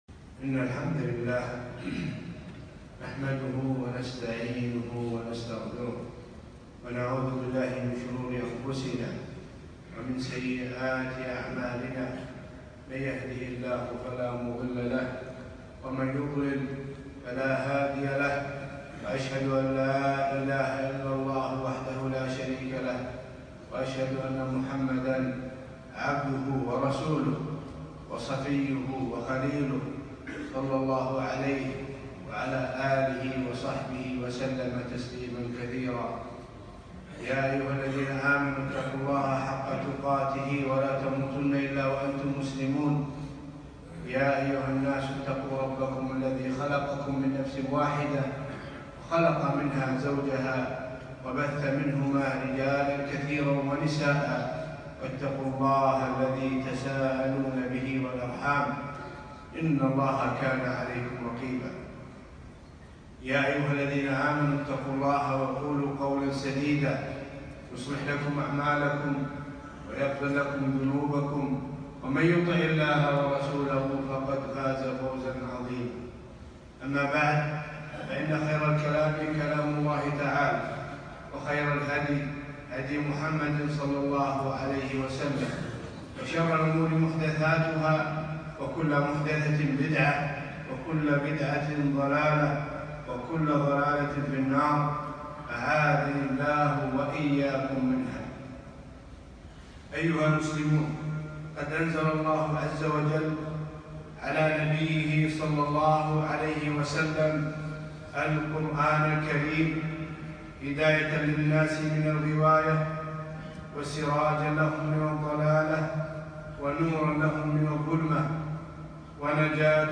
خطبة - فضل القرآن الكريم